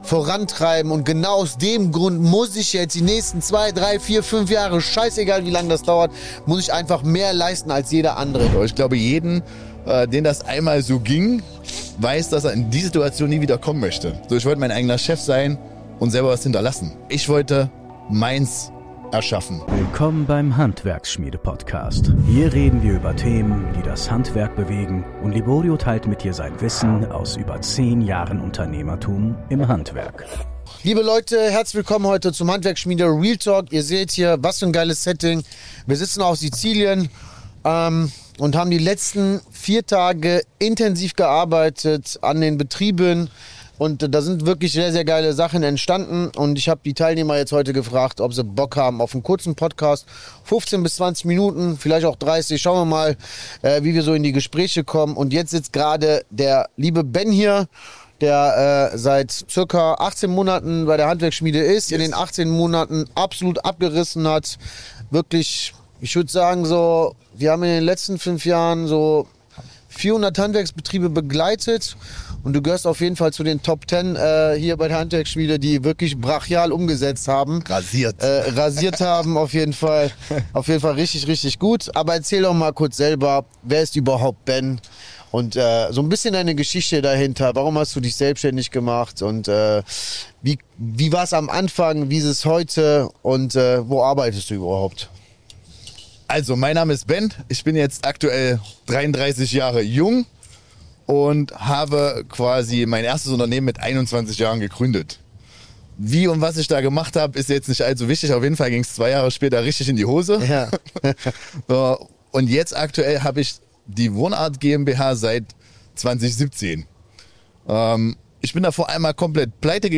Aus der Pleite zum Erfolgreichen Unternehmer | Interview